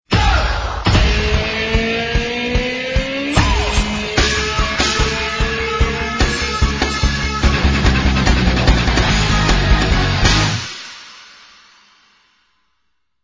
The recycled jingle collection: